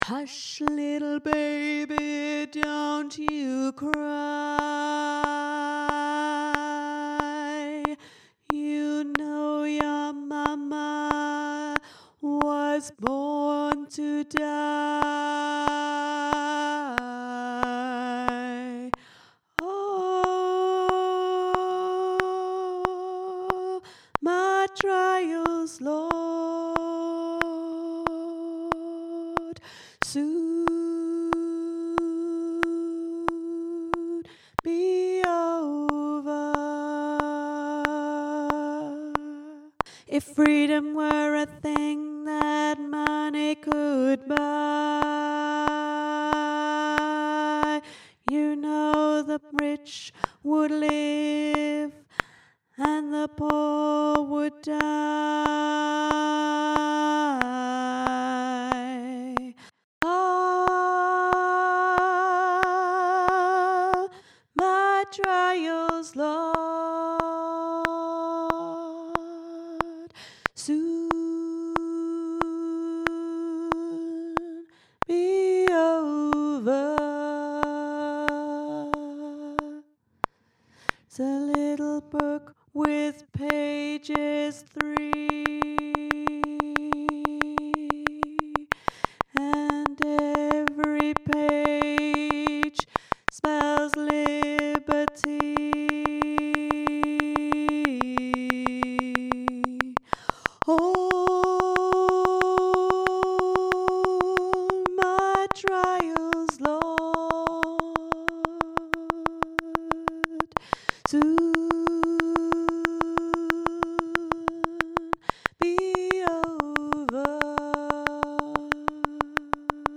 all-my-trials-all-my-trials-alto
all-my-trials-all-my-trials-alto.mp3